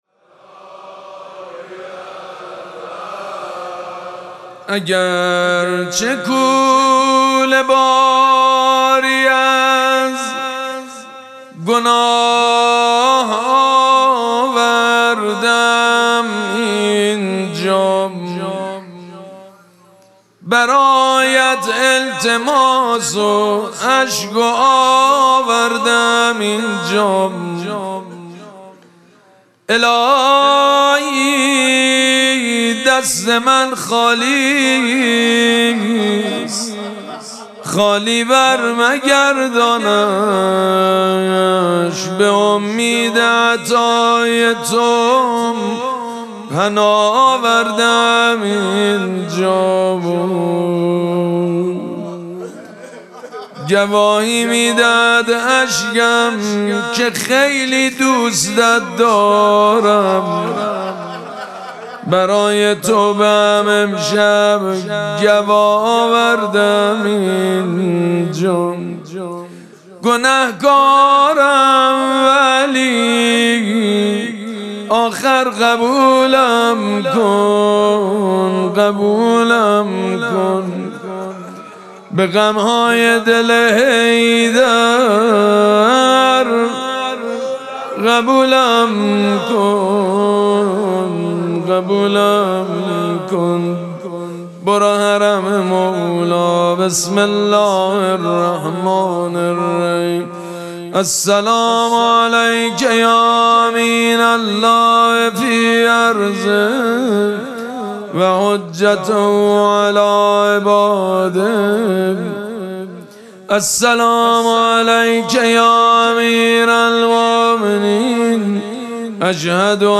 مراسم مناجات شب بیست و دوم ماه مبارک رمضان
حسینیه ریحانه الحسین سلام الله علیها
مداح
حاج سید مجید بنی فاطمه